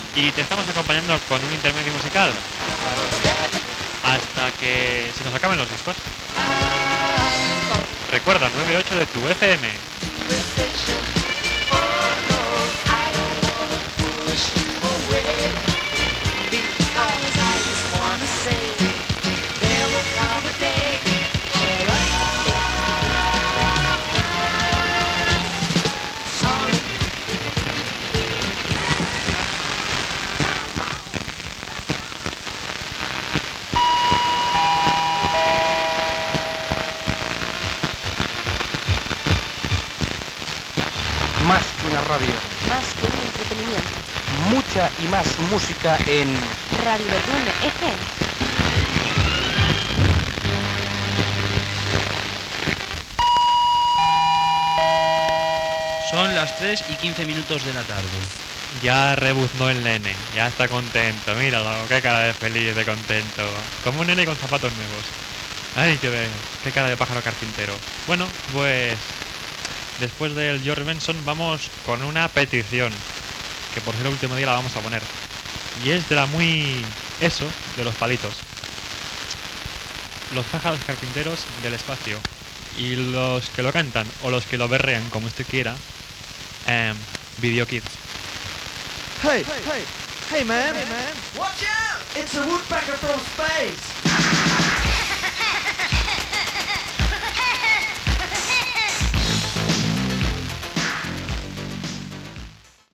Freqüència d'emissió, música, indicatiu, hora, tema musical.
Musical
FM